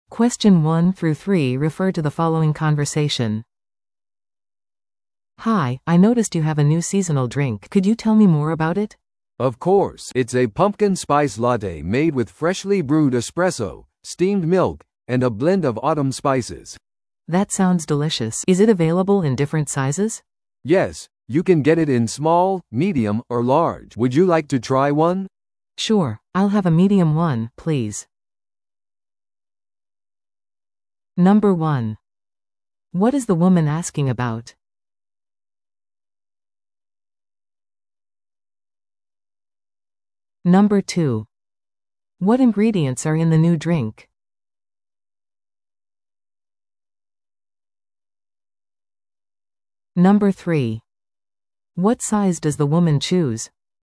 PART3は二人以上の英語会話が流れ、それを聞き取り問題用紙に書かれている設問に回答する形式のリスニング問題です。
No.1. What is the woman asking about?